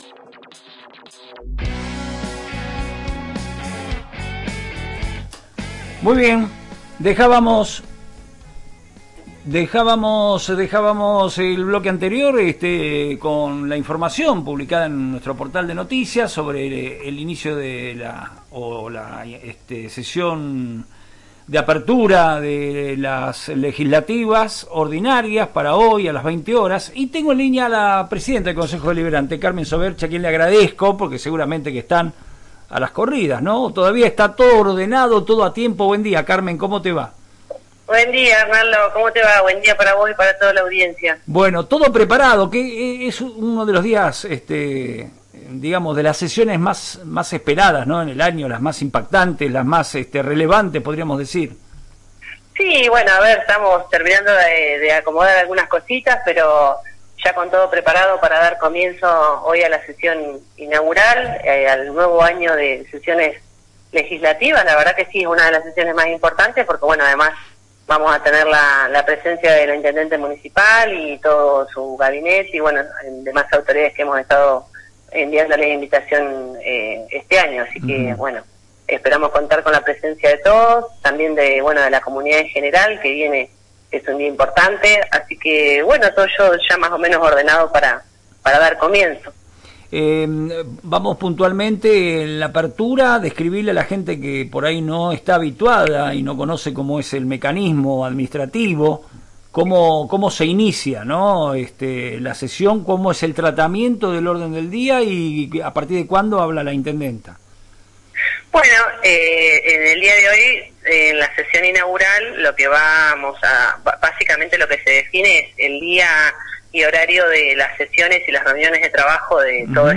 Carmen Soverchia, presidenta del Honorable Concejo Deliberante de Tornquist, brindó detalles sobre la sesión inaugural que contará con la presencia de la Intendenta municipal. Además de definir el calendario de trabajo para el año, analizó el clima político actual y marcó diferencias con el discurso del presidente de la Nación.